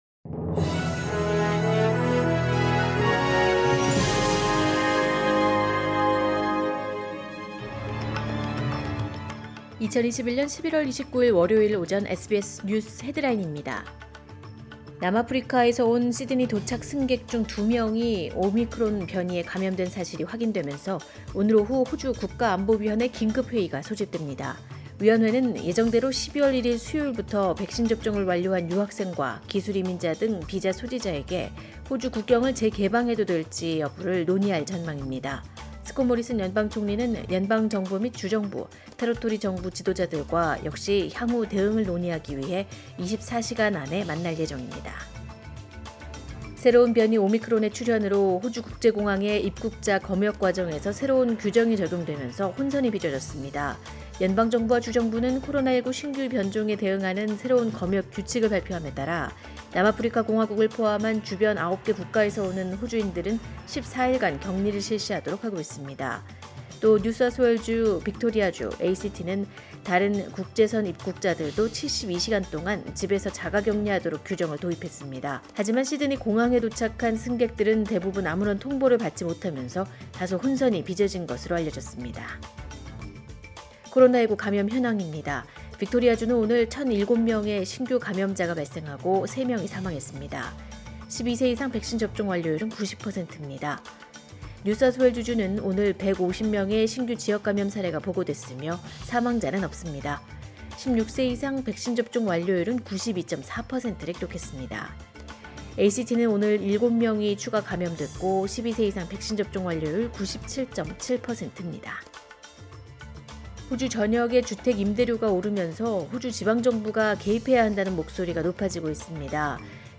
“SBS News Headlines” 2021년 11월 29일 오전 주요 뉴스
2021년 11월 29일 월요일 오전의 SBS 뉴스 헤드라인입니다.